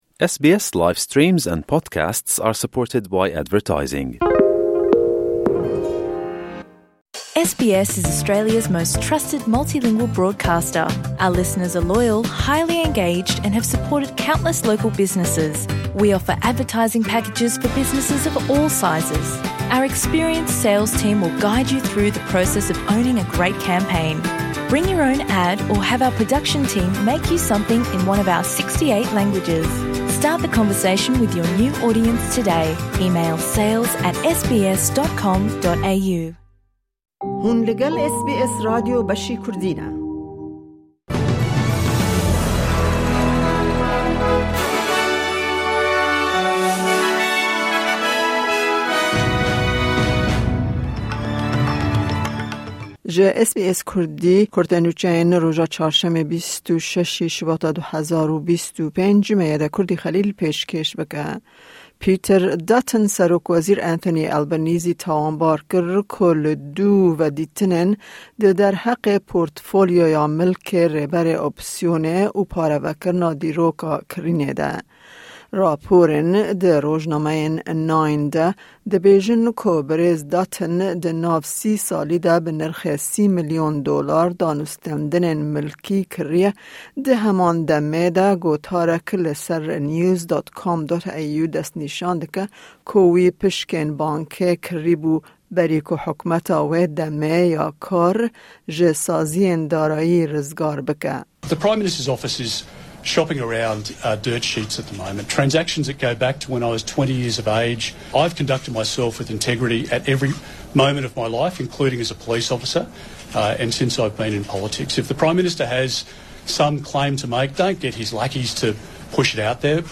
Kurte Nûçeyên roja Çarşemê,26î Şibata 2025